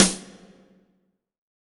WSNARE 2.wav